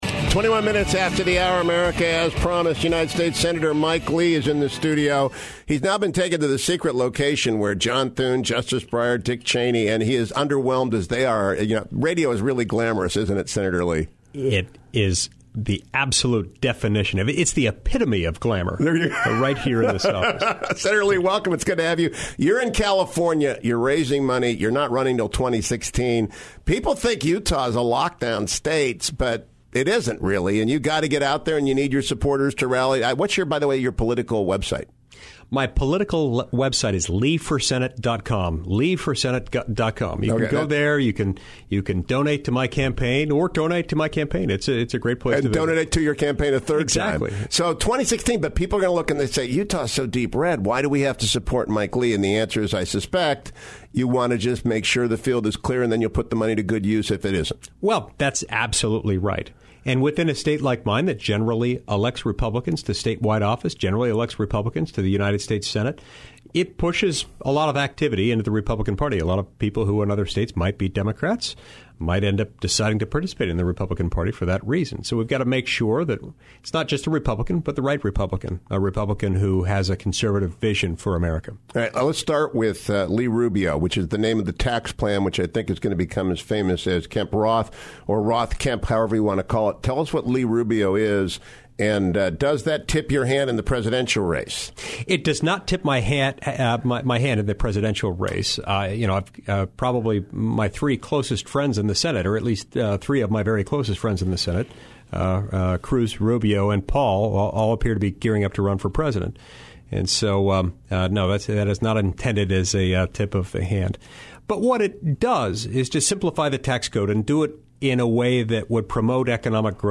The Audio: 03-30hhs-lee The Transcript: HH: As promised, United States Senator Mike Lee is in the studio.